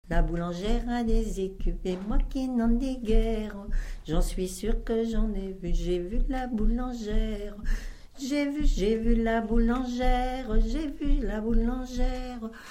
gestuel : danse
Pièce musicale inédite